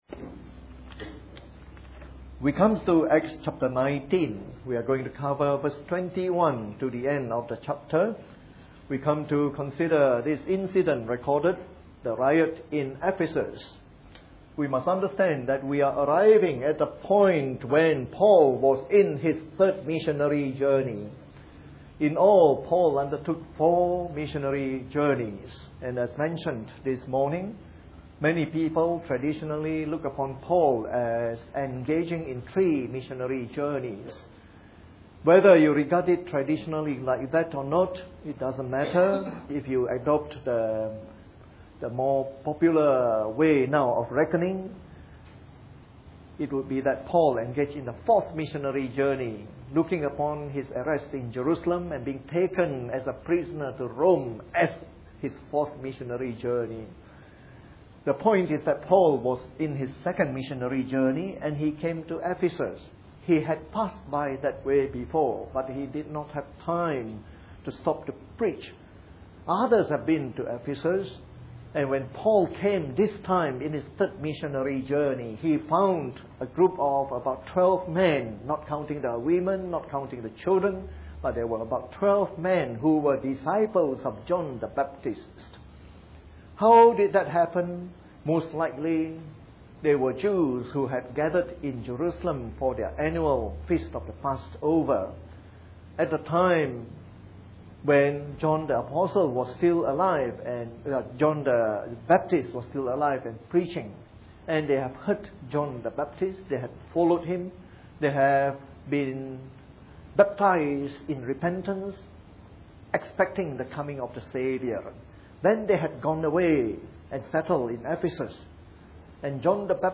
Part of our series on “The Acts of the Apostles” delivered in the Evening Service.